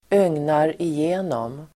Uttal: [²'öng:nar]